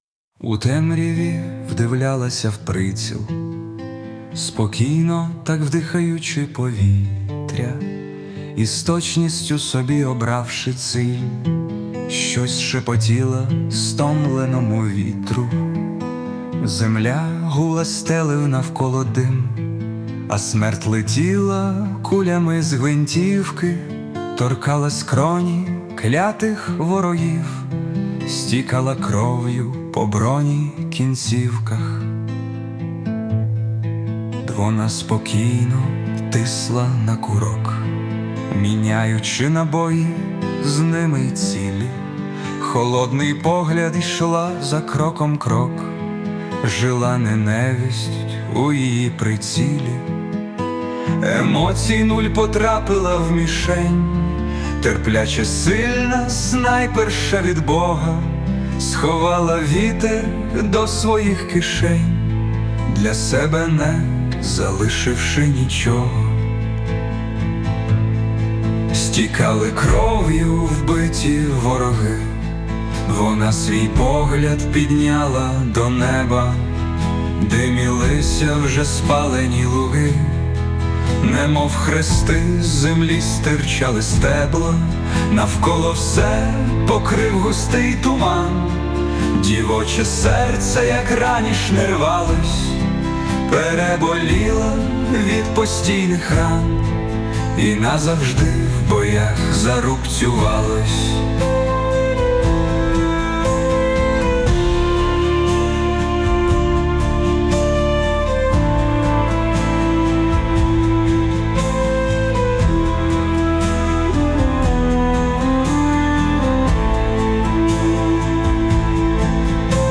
Пісні на військову тематику